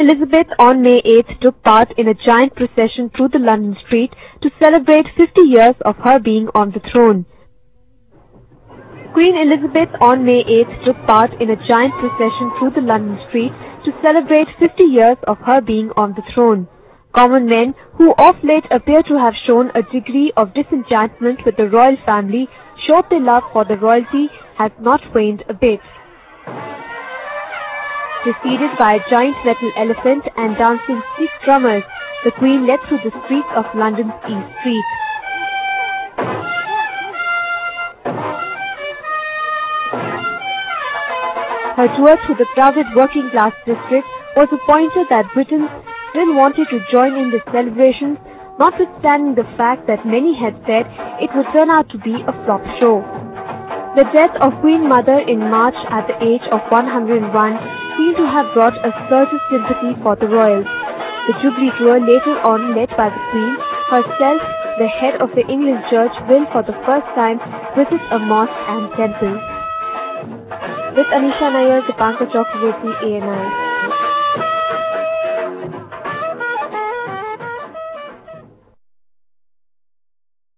Elizabeth joins a street party in London to celebrate her 50th year on throne.